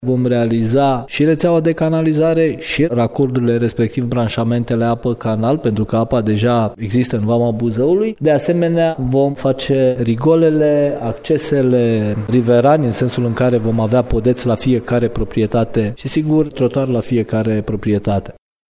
Primarul localității, Tiberiu Chirilaș.